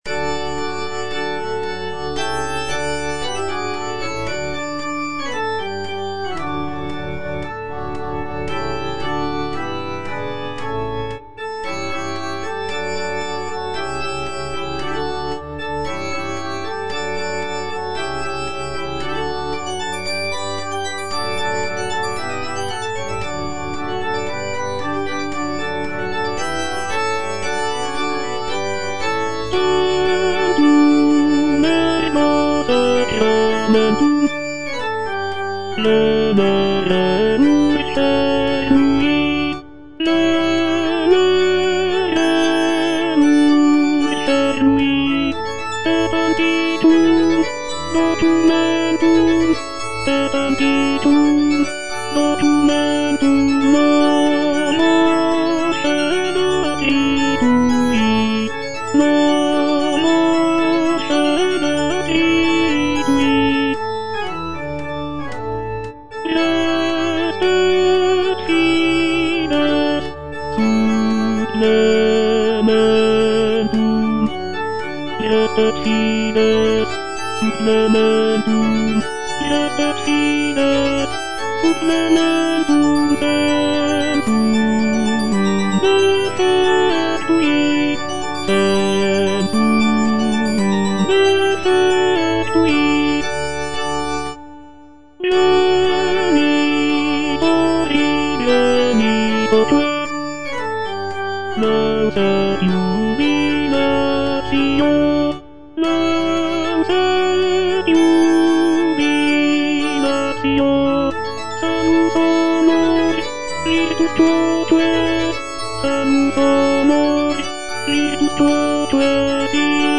W.A. MOZART - TANTUM ERGO KV197 Tenor (Voice with metronome) Ads stop: auto-stop
"Tantum ergo KV197" is a sacred choral work composed by Wolfgang Amadeus Mozart in 1774.
With its serene and contemplative character, "Tantum ergo KV197" remains a testament to Mozart's exceptional talent in composing religious music.